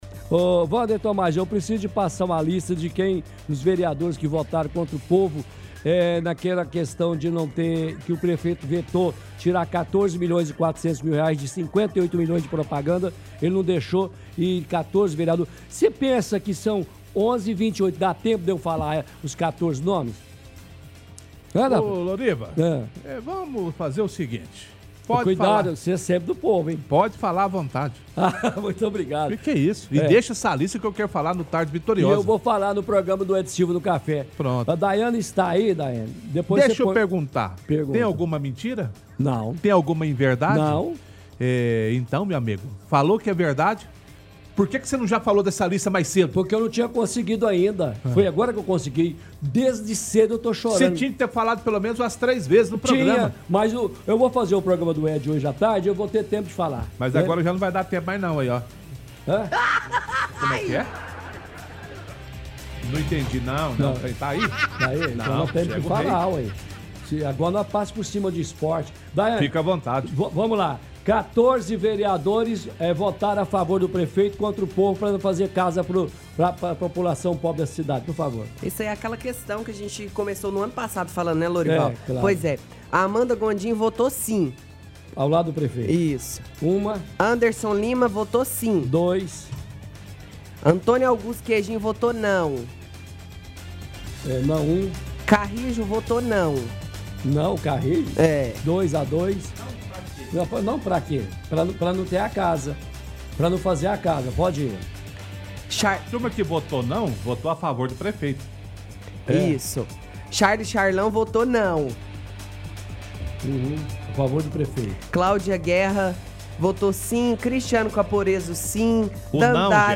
Rádio